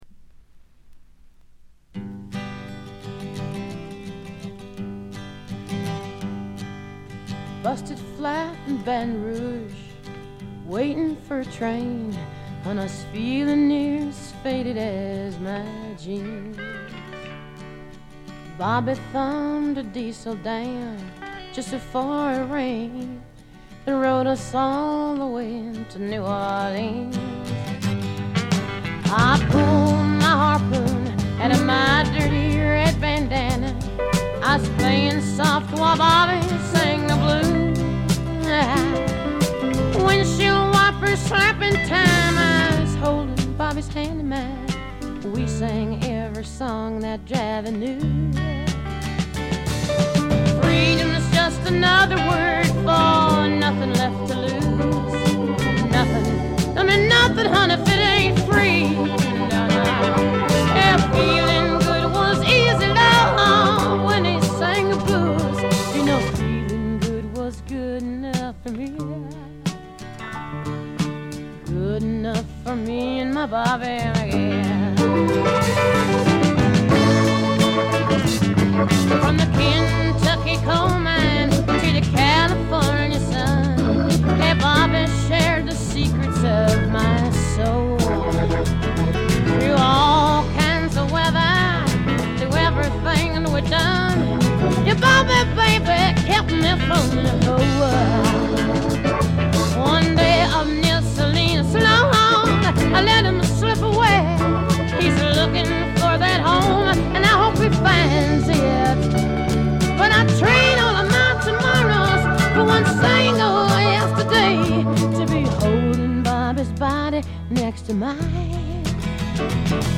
Vocals、Acoustic Guitar
Piano
Organ
Drums